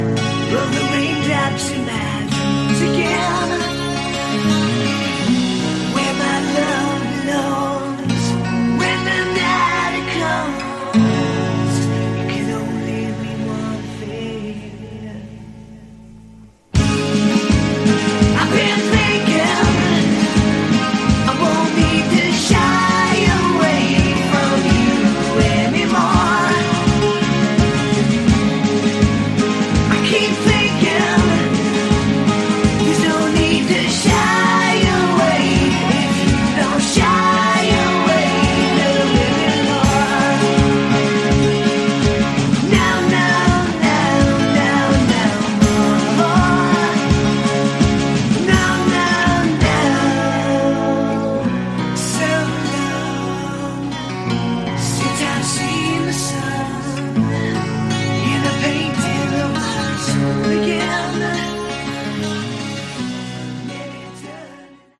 Category: Glam
vocals
guitars
bass
drums